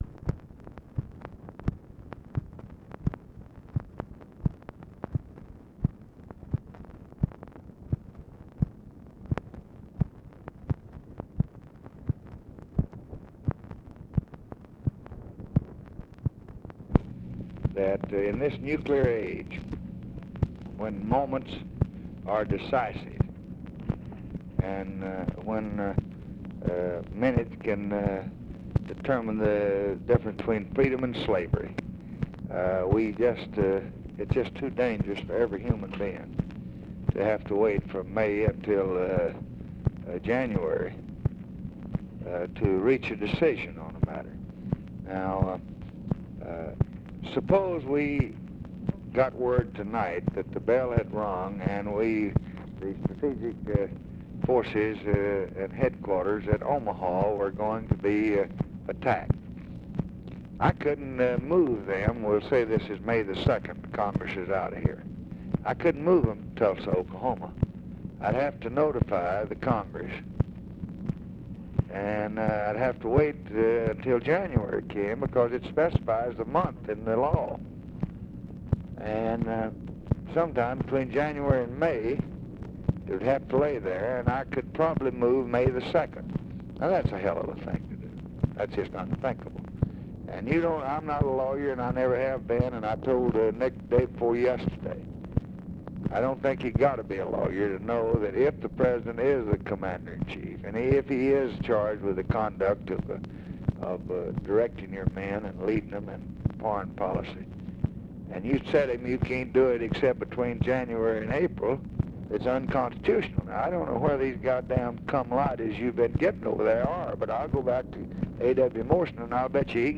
Conversation with OFFICE CONVERSATION, August 21, 1965
Secret White House Tapes